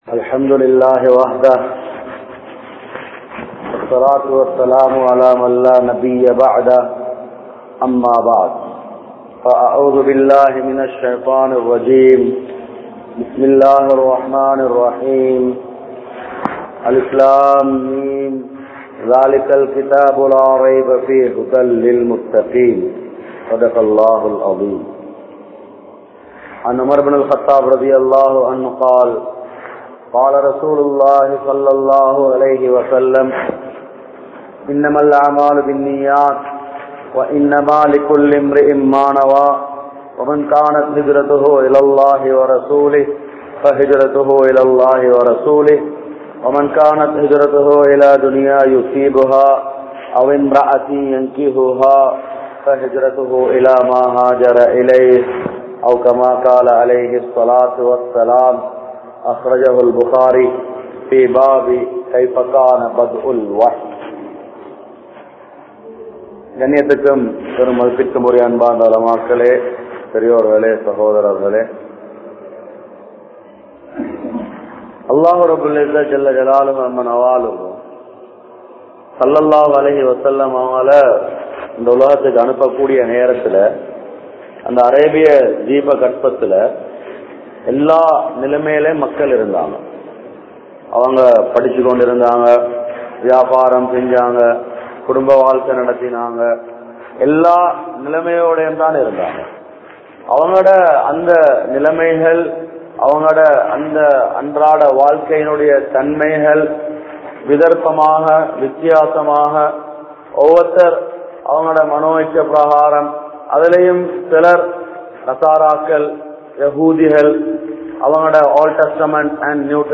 Barakath Niraintha Vaalkai Veanduma? (பரக்கத் நிறைந்த வாழ்க்கை வேண்டுமா?) | Audio Bayans | All Ceylon Muslim Youth Community | Addalaichenai
Nelliyagama Jumua Masjidh